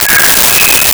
Megaphone Feedback 05
Megaphone Feedback 05.wav